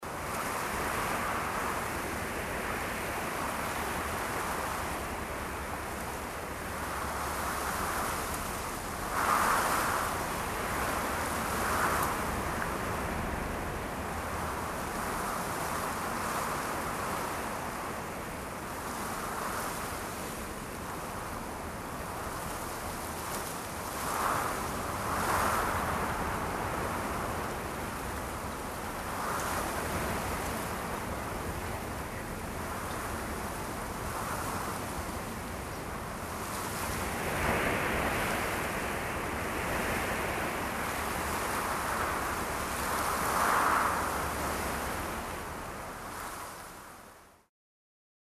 Звуки листьев
Шелест осенней листвы, легкий ветер в кронах деревьев или хруст под ногами — идеально для расслабления, работы или творчества.